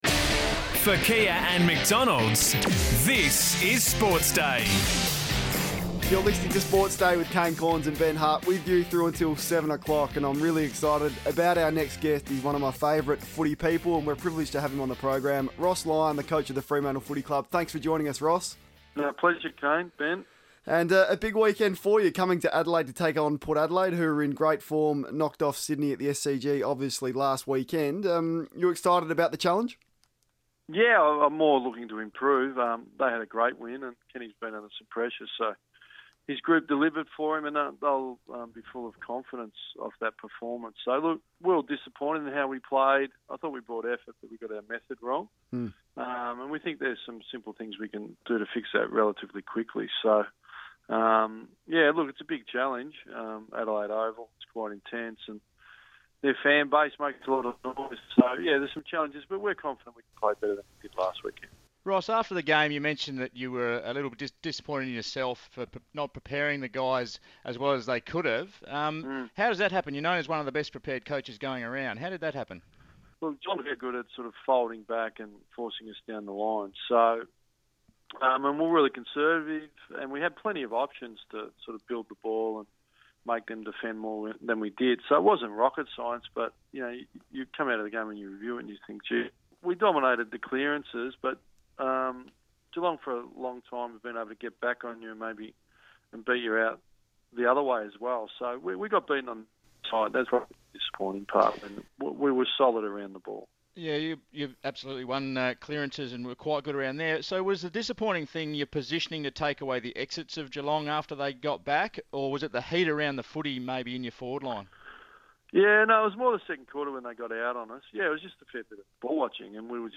Ross Lyon spoke to Sports Day in the lead up to their clash with Port Adelaide